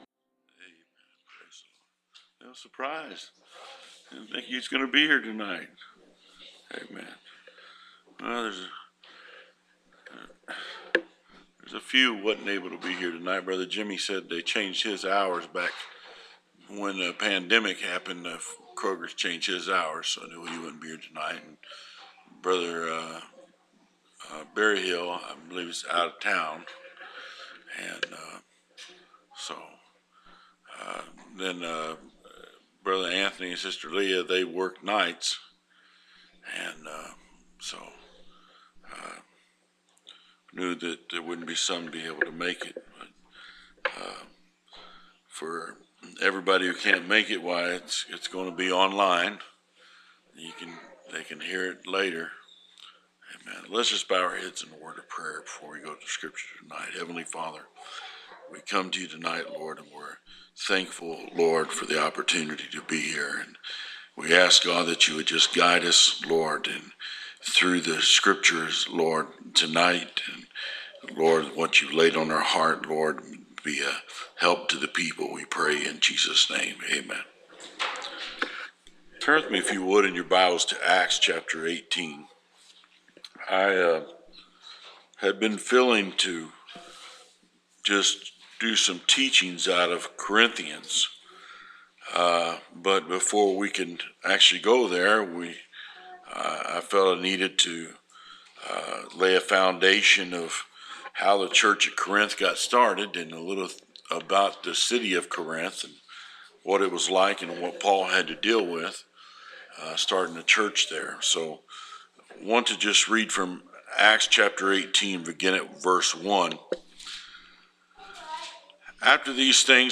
Preached June 8th, 2023